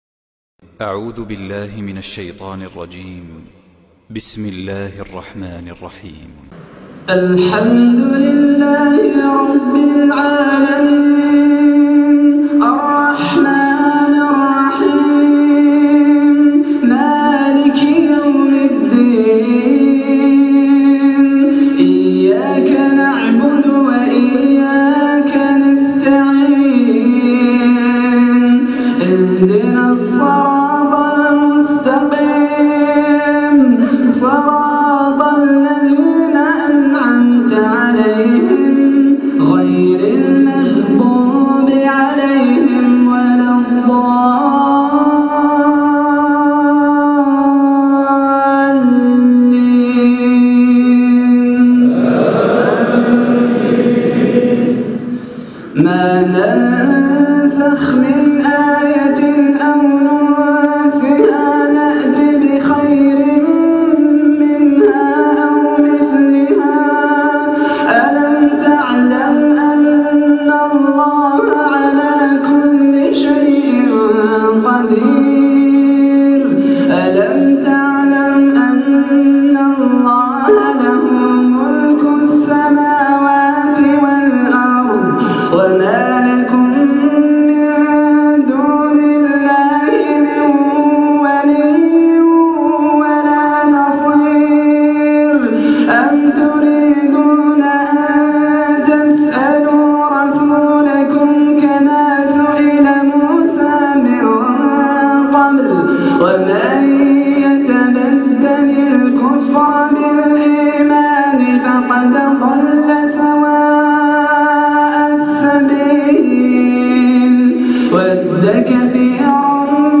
تلاوة من سورة البقرة
من صلاة العشاء